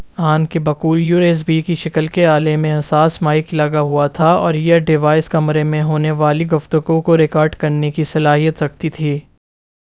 deepfake_detection_dataset_urdu / Spoofed_TTS /Speaker_08 /114.wav